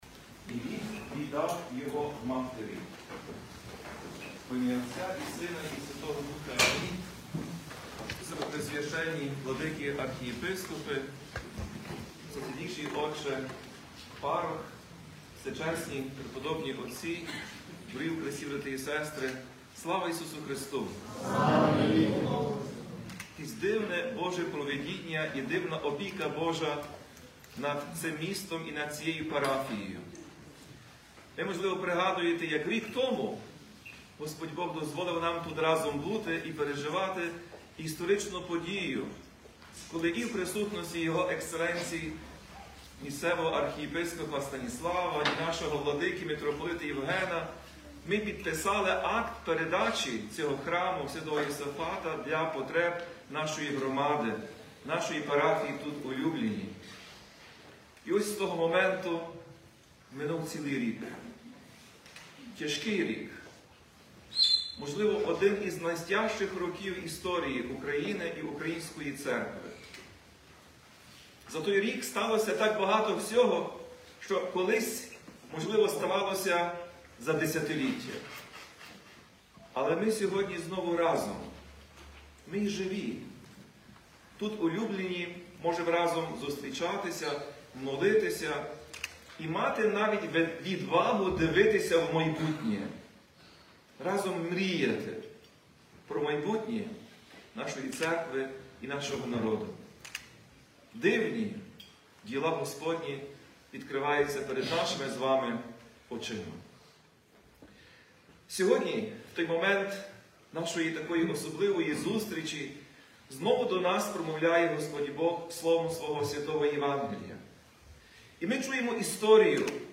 Проповідь Блаженнішого Святослава у 20-ту неділю після Зіслання Святого Духа